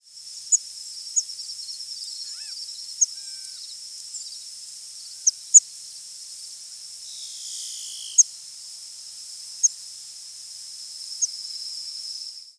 Prairie Warbler diurnal flight calls
Diurnal calling sequences:
Bird in flight with Laughing Gull and Boat-tailed Grackle calling in the background.